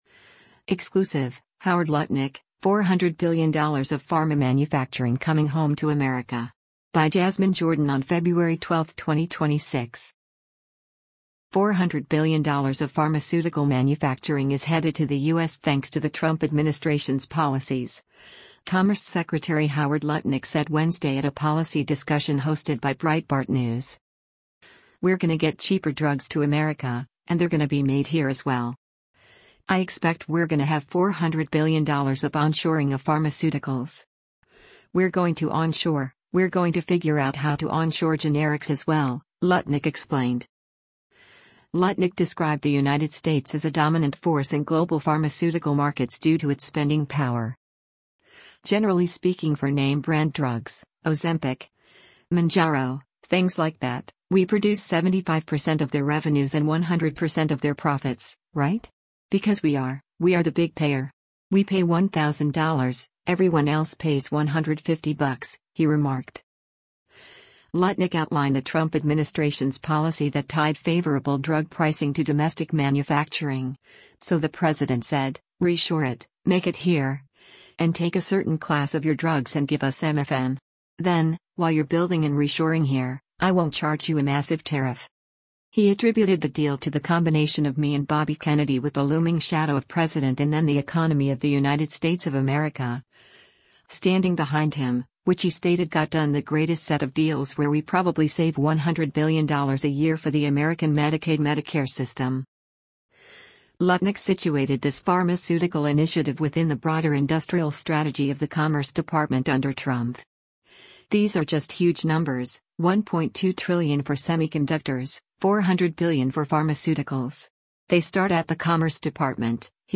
Four hundred billion dollars of pharmaceutical manufacturing is headed to the U.S. thanks to the Trump administration’s policies, Commerce Secretary Howard Lutnick said Wednesday at a policy discussion hosted by Breitbart News.